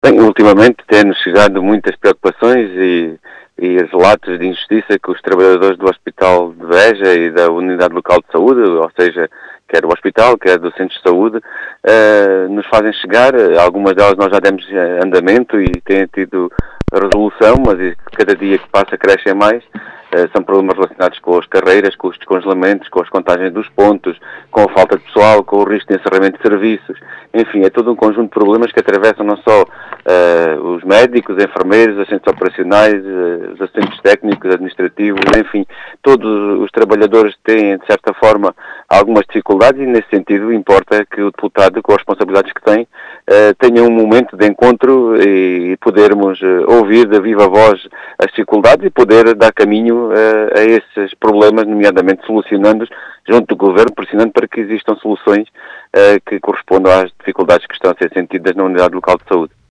As explicações foram deixadas pelo deputado João Dias, que quer conhecer as preocupações dos trabalhadores da ULSBA.